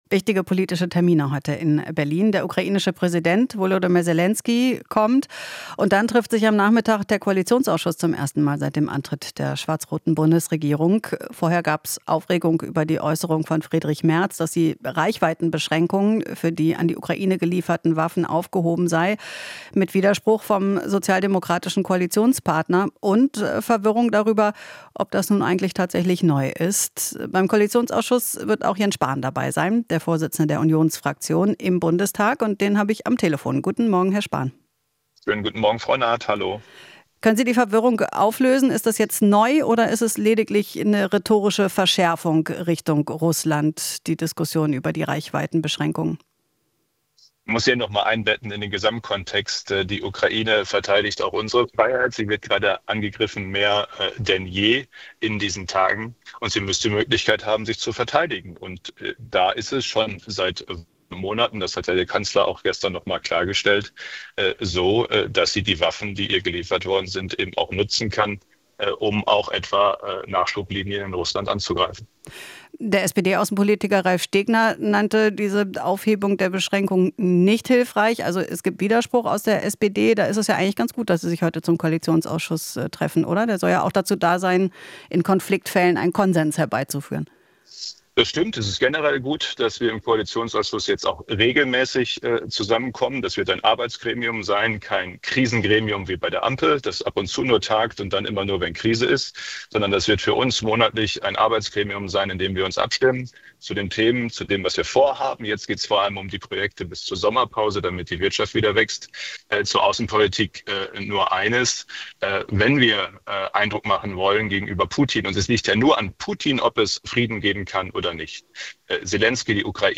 Interview - Spahn (CDU): Selenskyj-Besuch klares Zeichen der Unterstützung